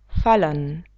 padat (640x575)padat fallen [faln]